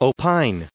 added pronounciation and merriam webster audio
906_opine.ogg